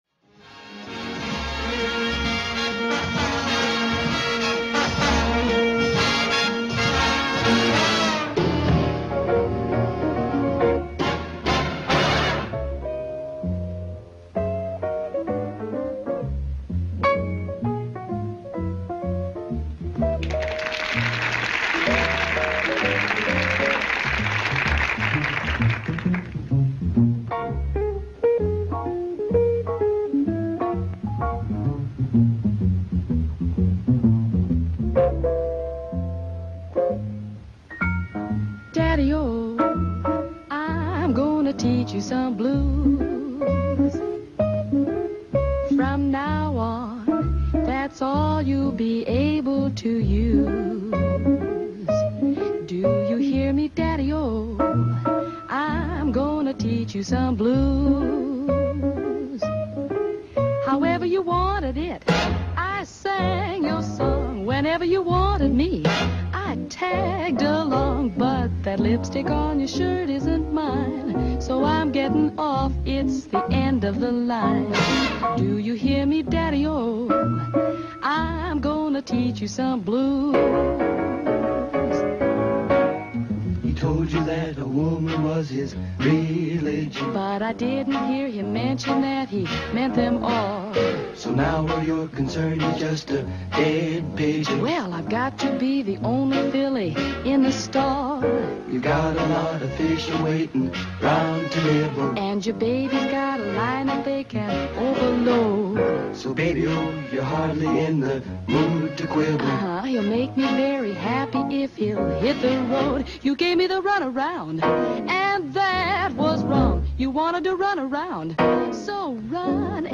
En él, hemos celebrado el cuarto aniversario de Blues en las ondas con Blues sugeridos por todos vosotros, una suerte de cadáver exquisito con un resultado excelente.